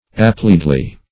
appliedly - definition of appliedly - synonyms, pronunciation, spelling from Free Dictionary Search Result for " appliedly" : The Collaborative International Dictionary of English v.0.48: Appliedly \Ap*pli"ed*ly\, adv.